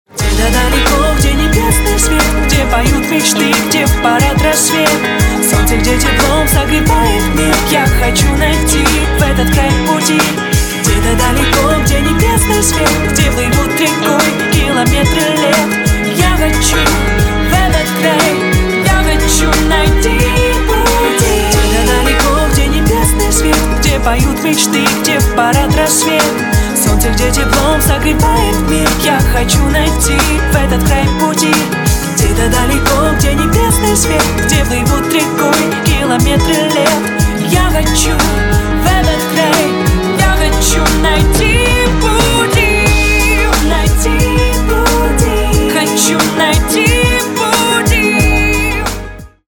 ритмичные
женский вокал
мелодичные
dance
спокойные
красивая мелодия
club
красивый голос
vocal